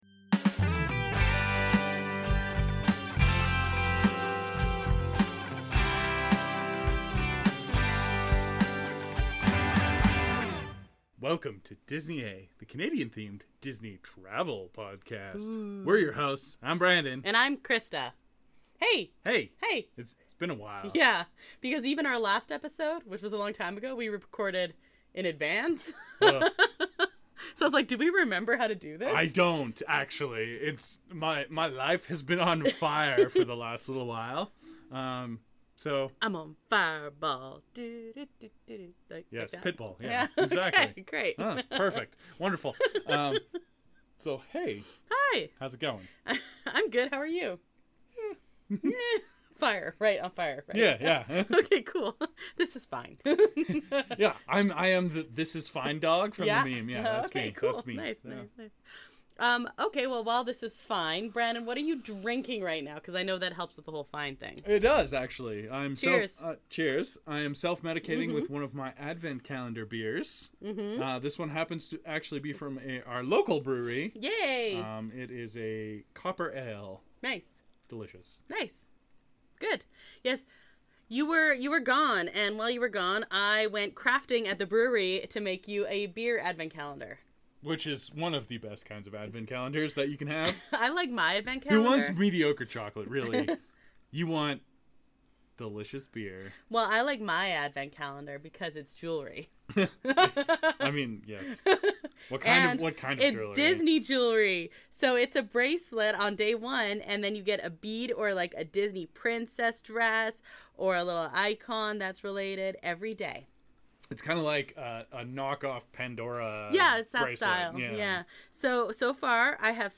FIREBALL and Other News Episode 187, Dec 09, 2024, 09:03 AM Headliner Embed Embed code See more options Share Facebook X Subscribe Welcome back to "Disney, Eh?", the Disney travel podcast from a Canadian perspective.